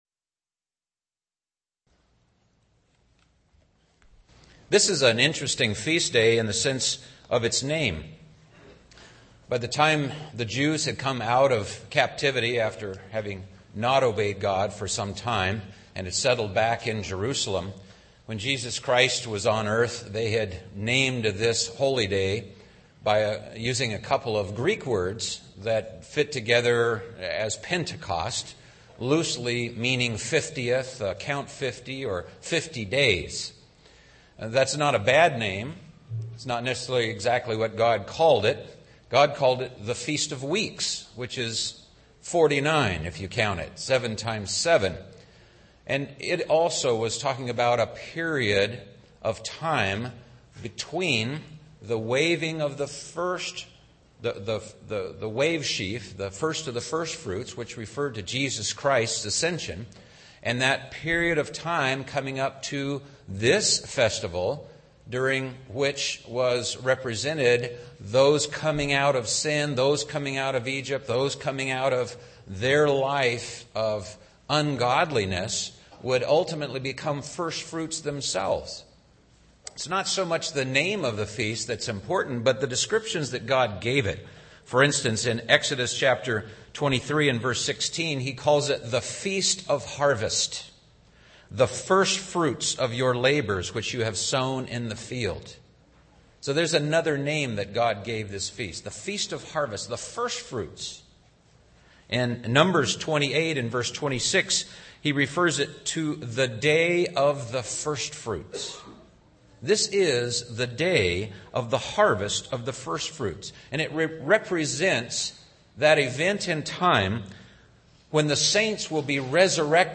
This message was given on the Feast of Pentecost.